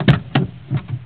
CAR-DOR.WAV